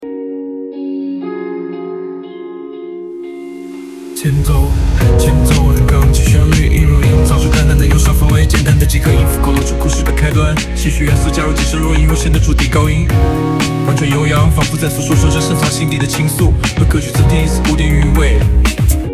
- 流行元素：以轻柔的钢琴旋律引入，营造出淡淡的忧伤氛围，简单的几个音符勾勒出故事的开端。
- 戏曲元素：加入几声若隐若现的竹笛高音，婉转悠扬，仿佛在诉说着深藏心底的情愫，为歌曲增添一丝古典韵味。
人工智能生成式歌曲